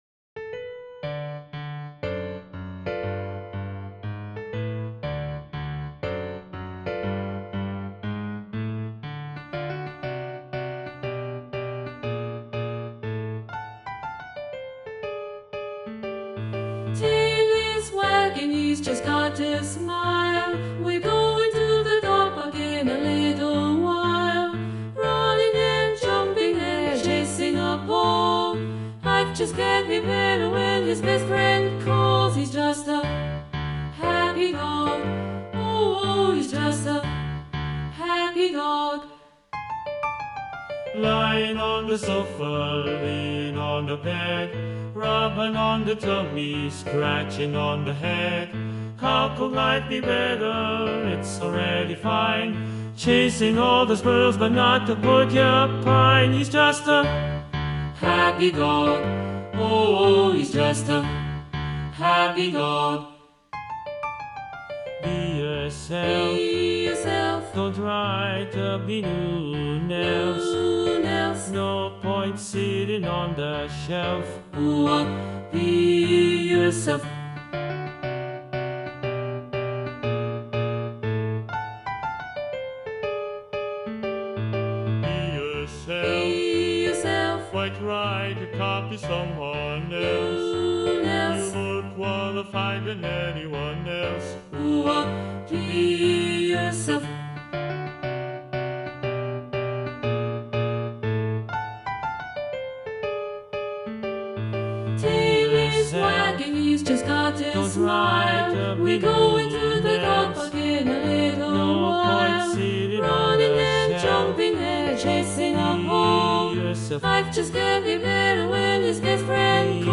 SATB (4 voix) ; Partition complète.
Jazz choral. Blues.
Caractère de la pièce : léger ; sautillant
Instruments : Piano (1)
Tonalité : ré majeur